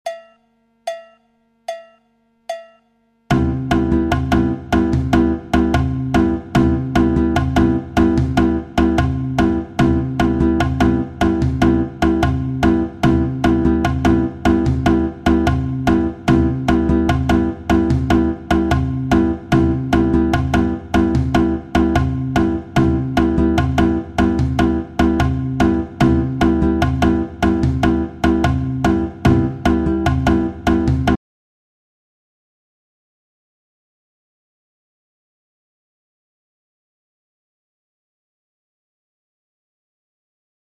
La bossa nova figure 2
Une variante bossa nova 2 , seule avec le tambourim, surdo.